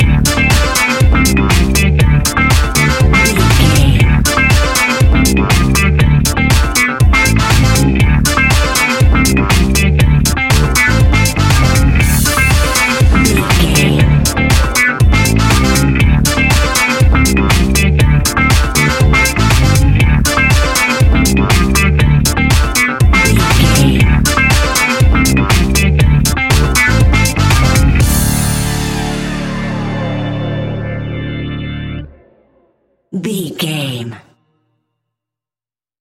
Aeolian/Minor
D
groovy
futuristic
hypnotic
uplifting
bass guitar
electric guitar
drums
synthesiser
funky house
disco house
electro funk
bright
energetic
upbeat
synth leads
Synth Pads
synth bass
drum machines